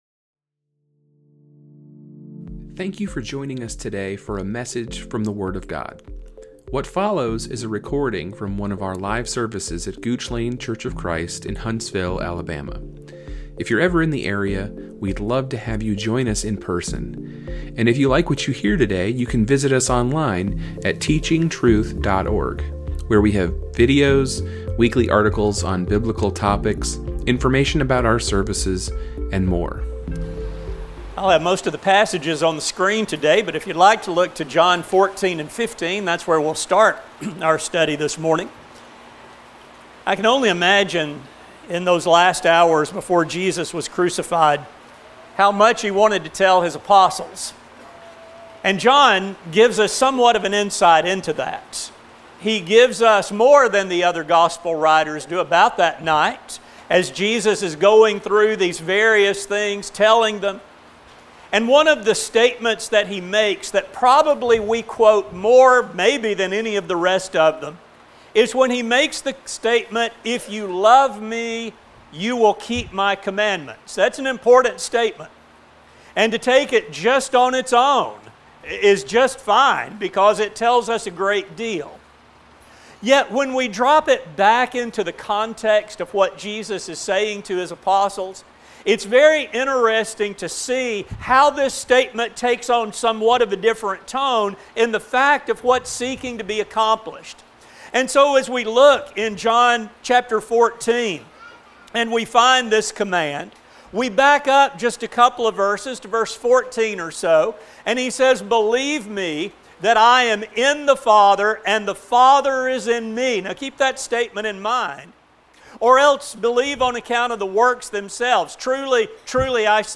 This sermon will discuss the respect for God that arises from our love for Him. It will explore how appreciation for God’s commands reveals our love for Him and our trust in His plan.